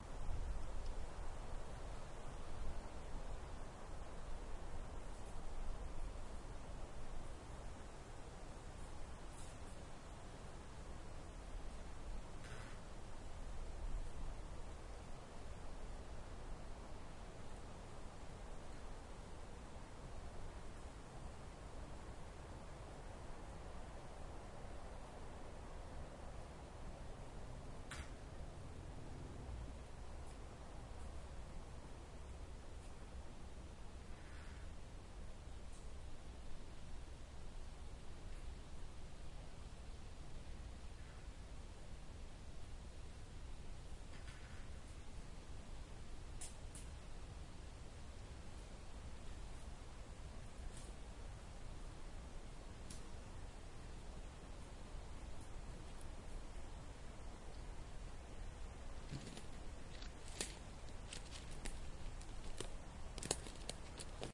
森林中的人们 " 在风的森林中接近 (1人)
描述：现场录音。一些森林环境和一个人接近麦克风的脚步声。
标签： 森林 步骤 树林 行走 脚步
声道立体声